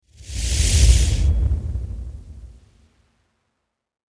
Soft Electronic Swoosh Sound Effect
Description: Soft electronic swoosh sound effect. Get a fast digital whoosh transition sound effect for your projects.
Soft-electronic-swoosh-sound-effect.mp3